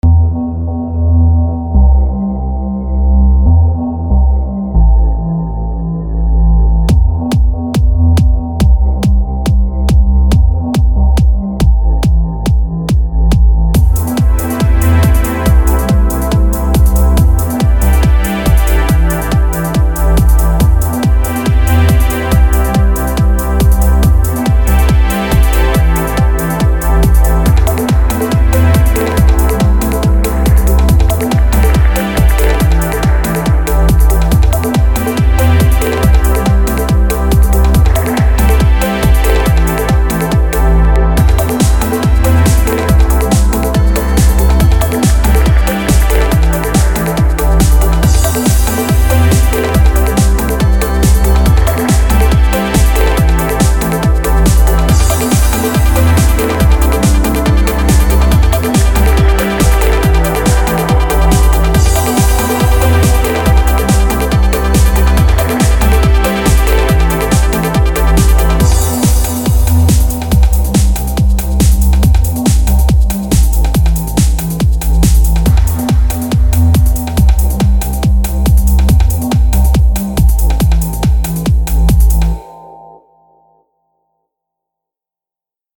Untz in your computer is a happy, musical take on the theme that manages to win out over many others.
3) "Untz in Your Computer" - I like the beat and pace, and the effective simplicity. The little blips are a great touch and add to the whole computer hardware atmosphere of many bits in motion.